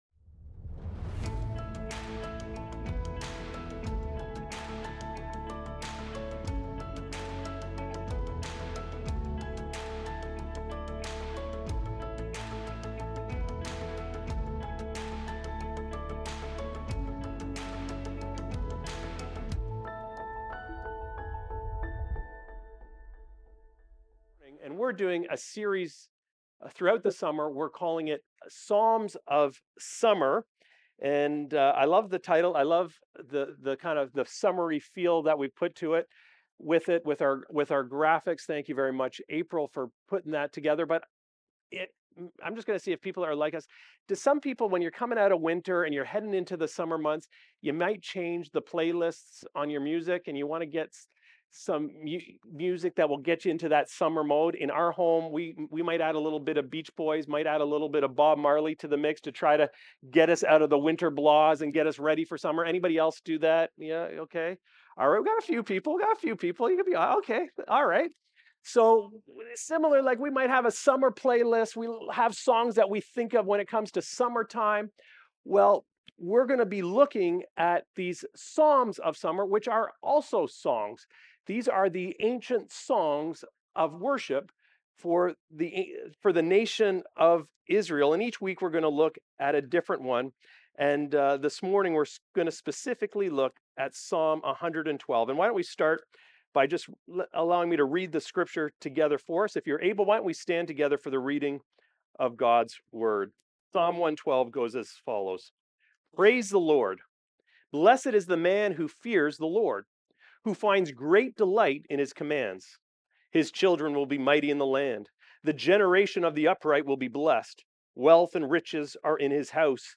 Recorded Sunday, July 20, 2025, at Trentside Bobcaygeon.